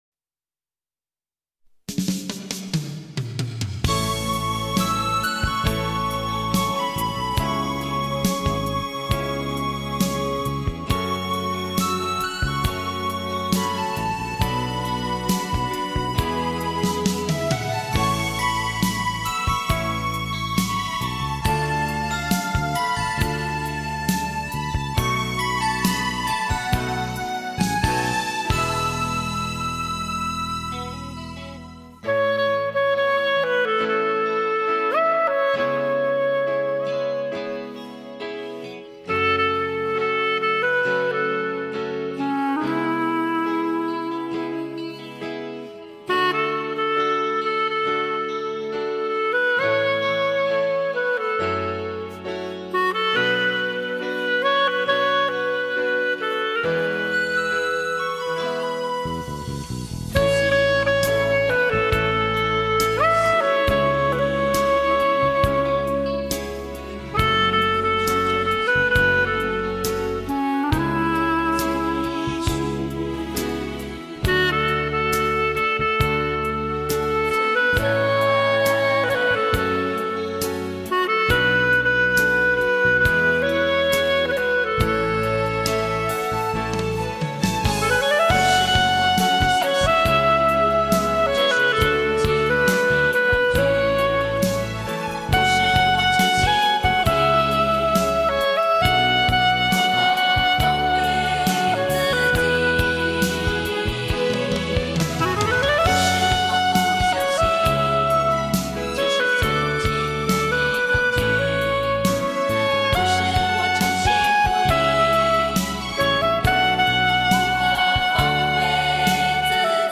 单簧管沿着时间的年轮轻吟浅唱。